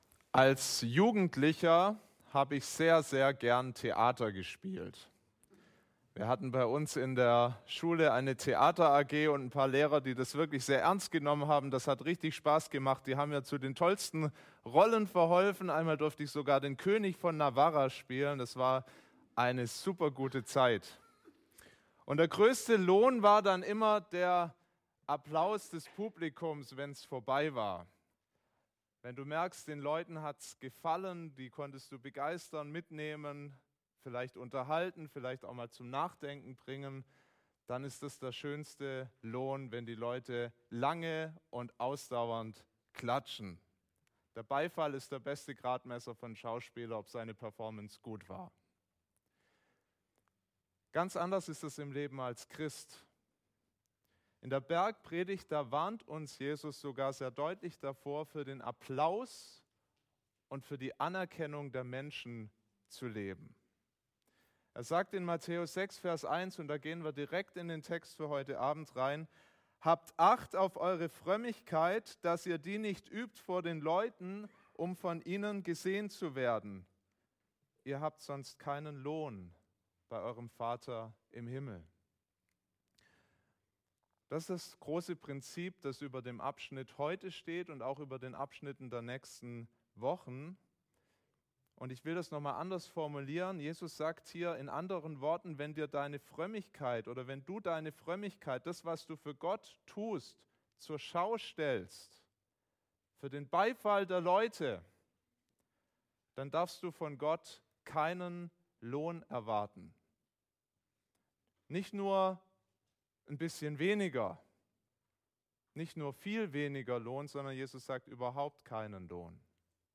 FeG München Mitte Predigt podcast